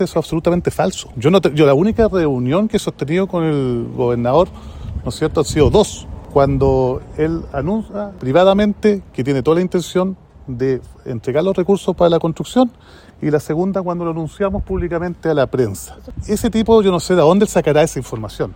Momentos después de conocer esta información, el alcalde de Temuco, Roberto Neira, convocó un punto de prensa, donde rechazó categóricamente esa acusación y negó que se haya sugerido actuar fuera de la normativa.
alcalde-neira.mp3